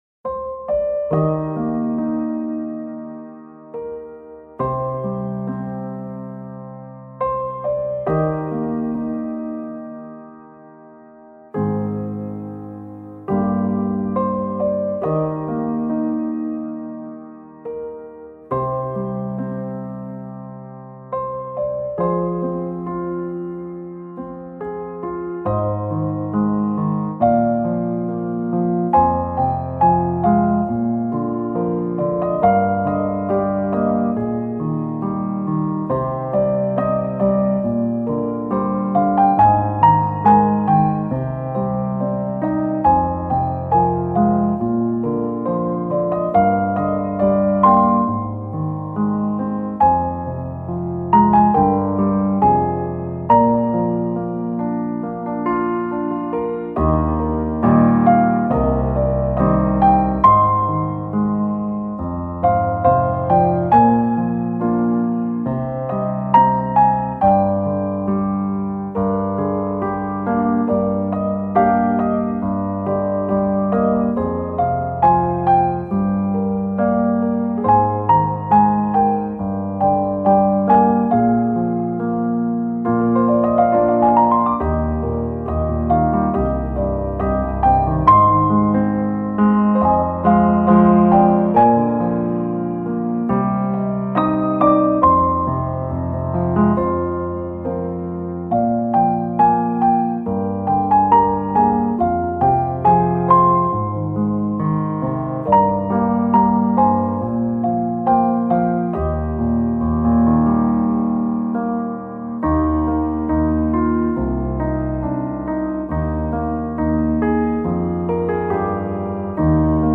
スローテンポのバラードピアノソロです。切なさ、愛しさ、静けさといったキーワードに合うコンテンツでいかがでしょうか。